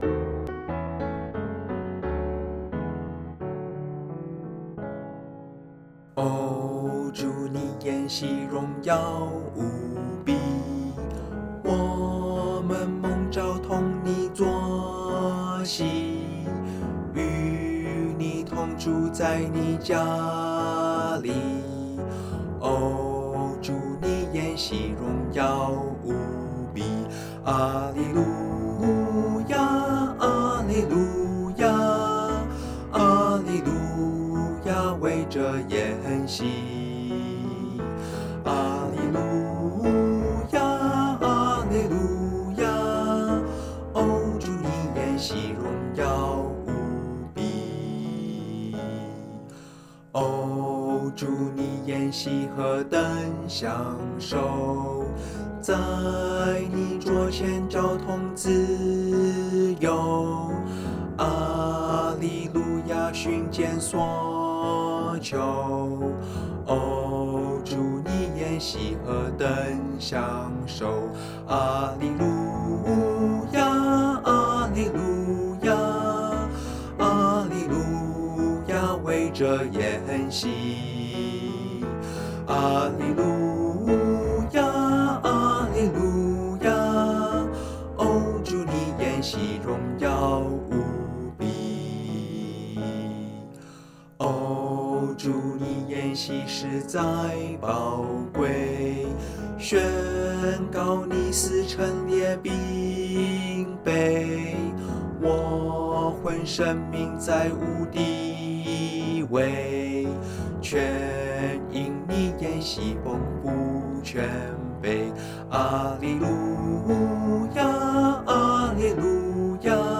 C大調